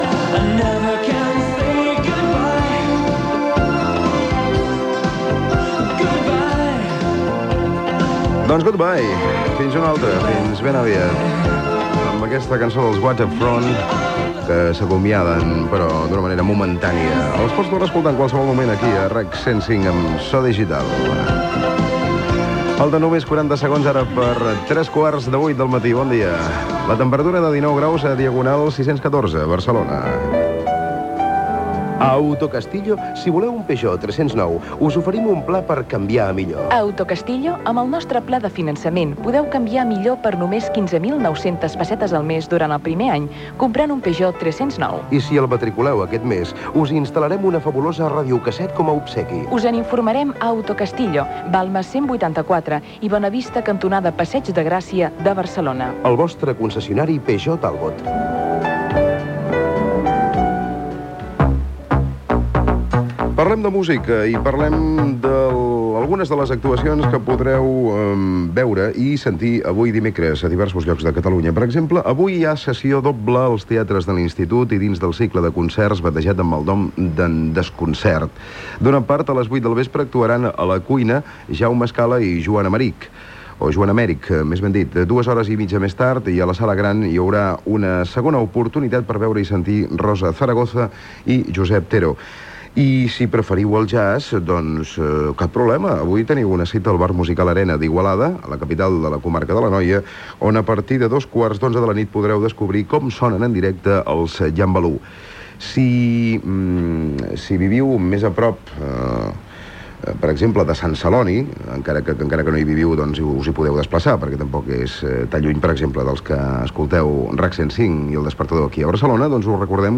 FM